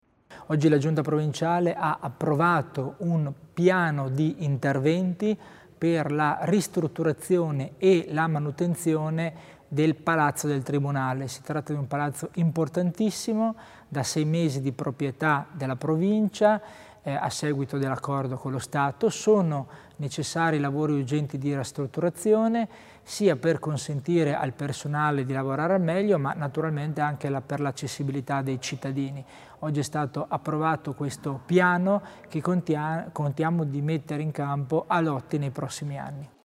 Il Vicepresidente Christian Tommasini elenca gli interventi di ristrutturazione del Tribunale di Bolzano